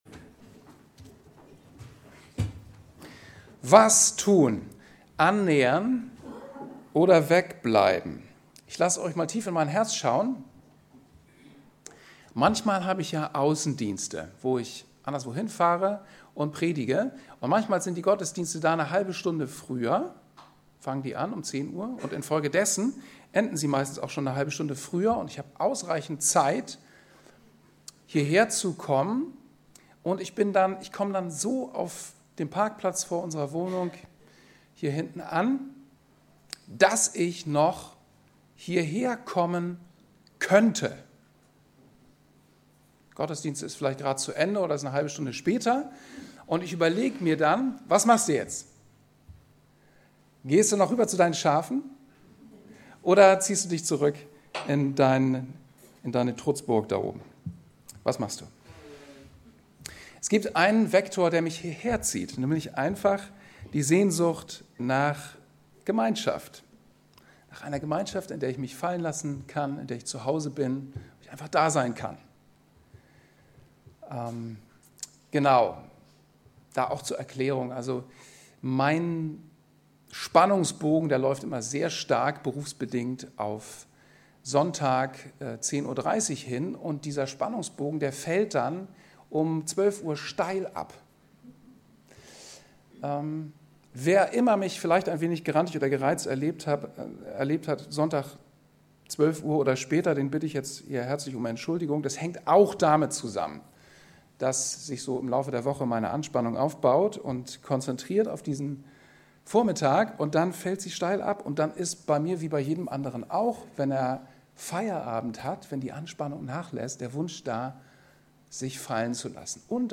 Predigt zur Jahreslosung 2016
PREDIGTEN